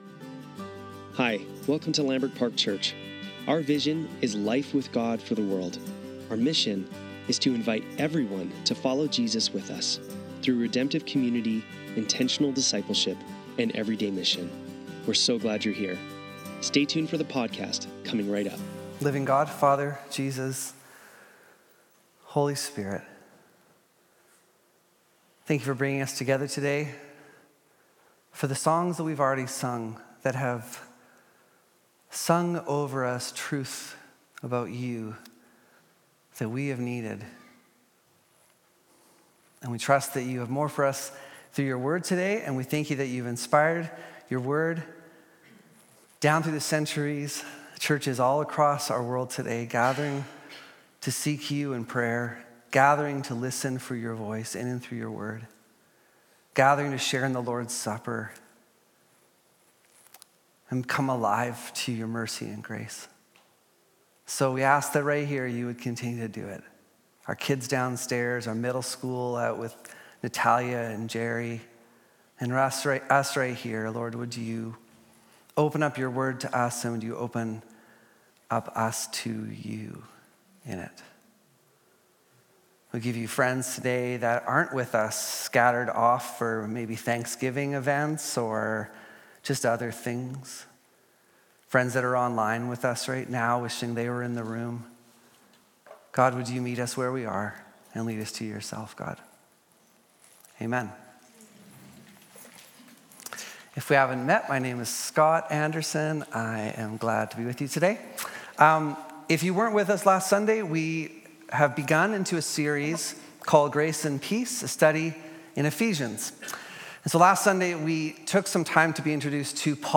Lambrick Sermons | Lambrick Park Church
Sunday Service - October 13, 2024